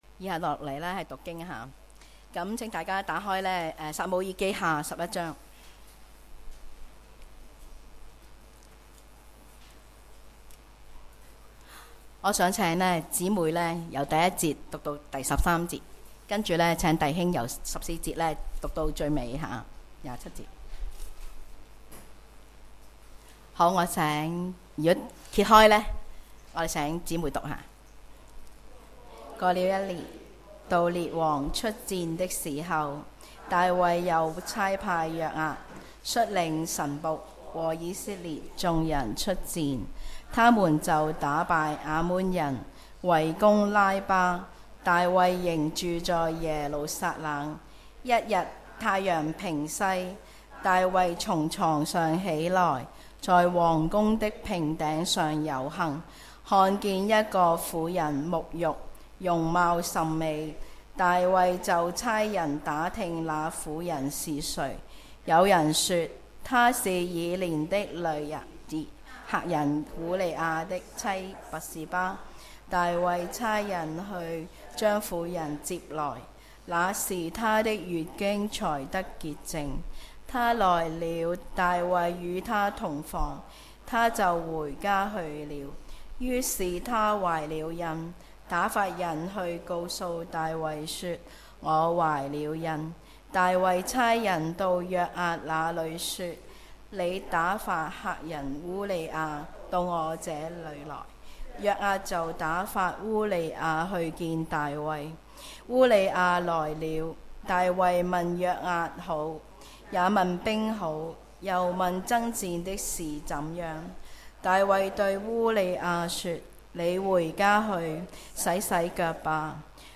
主日崇拜講道 – 大衛的跌倒